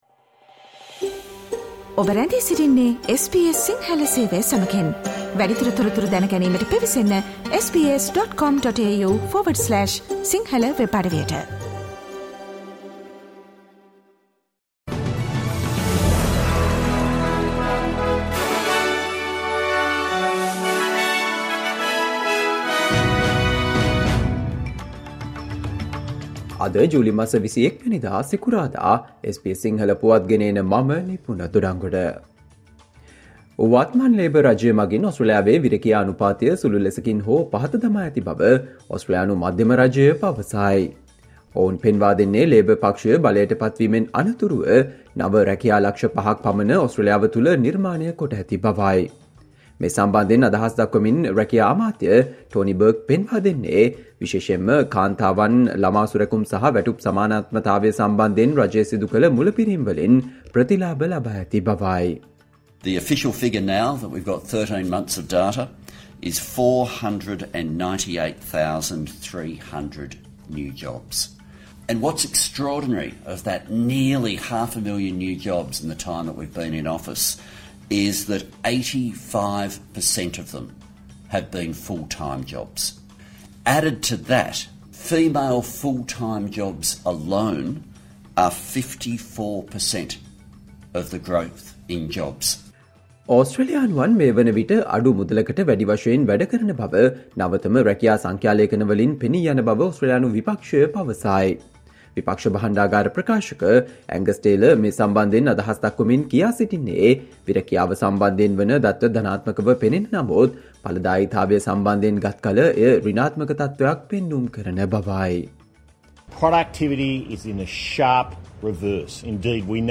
Australia news in Sinhala, foreign and sports news in brief - listen, today - Friday 21 July 2023 SBS Radio News